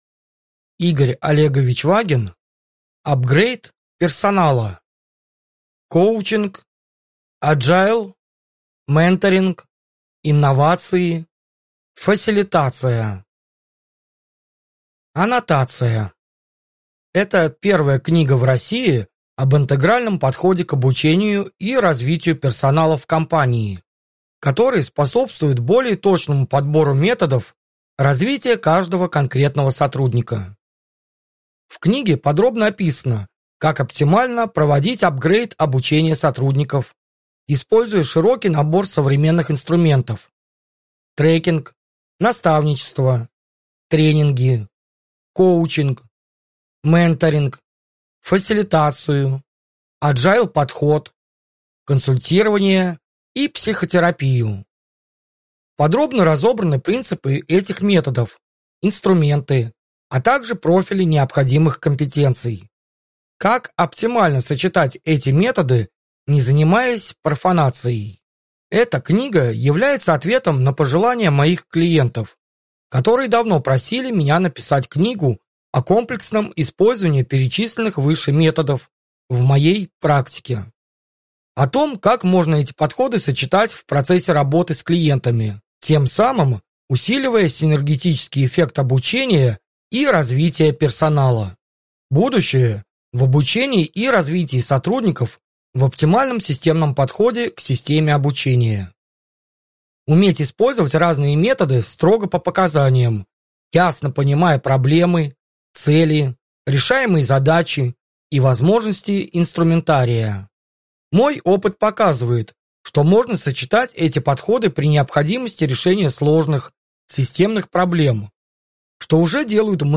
Аудиокнига Апгрейд персонала | Библиотека аудиокниг